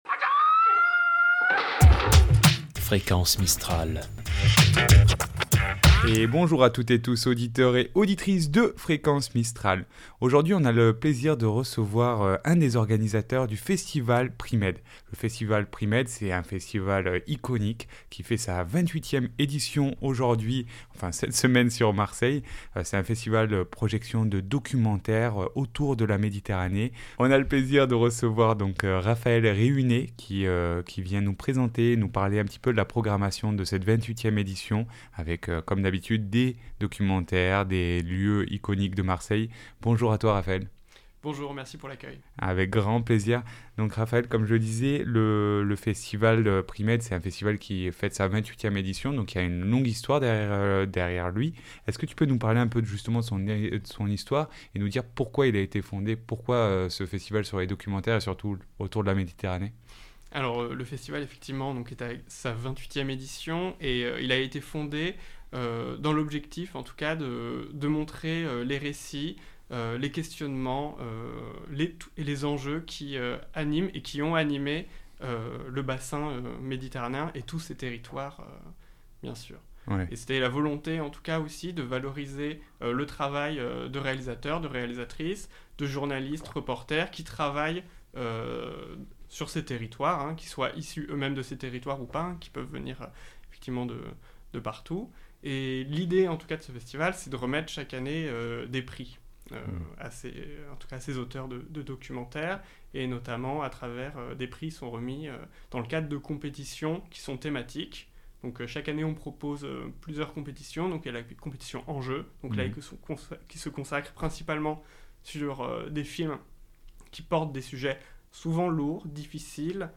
Festival PriMed Itw.mp3 (23.39 Mo)